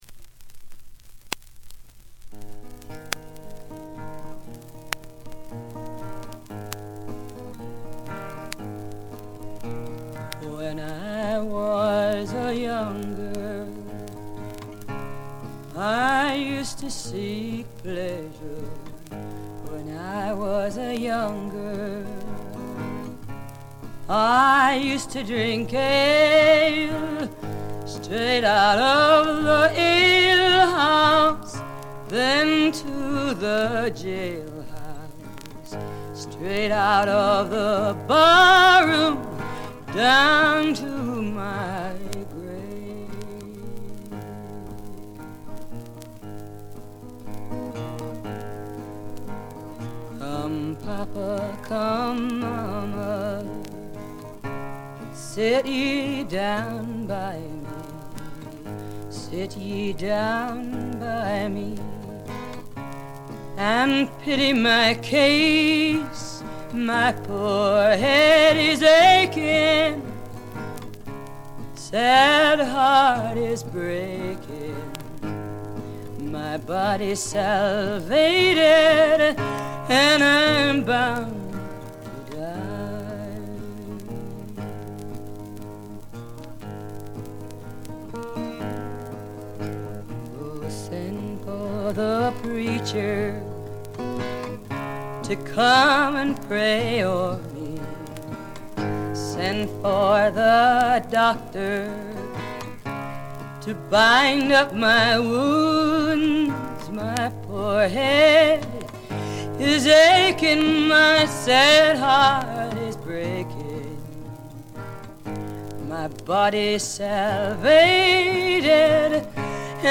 バックグラウンドノイズ、チリプチは常時大きめに出ます。
A面冒頭のパチ音以外は格別に目立つノイズはありません。
存在感抜群のアルト・ヴォイスが彼女の最大の武器でしょう。
試聴曲は現品からの取り込み音源です。